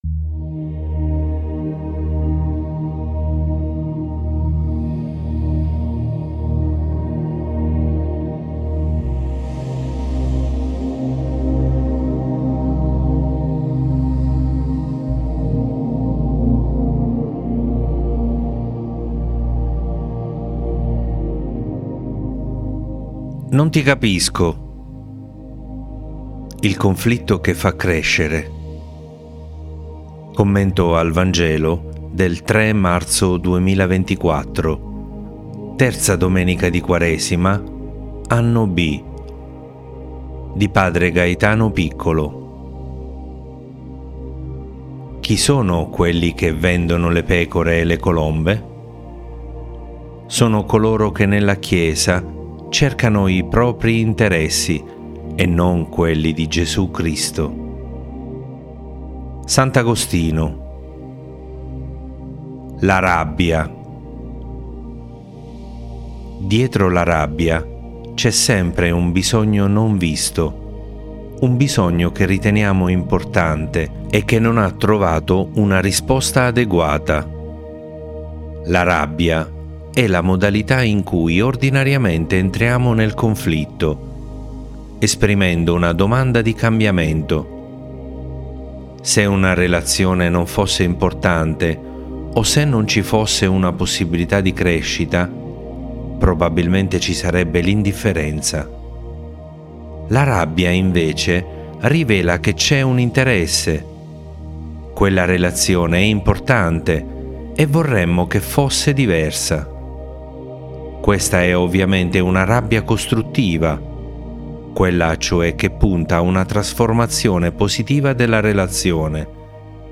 Commento al Vangelo del 3 marzo 2024